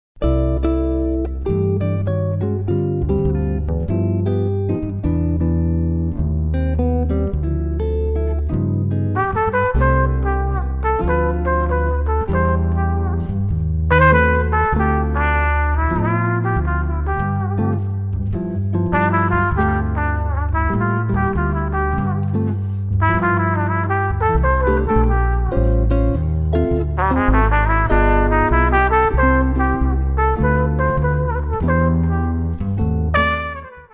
warm trumpet
double bass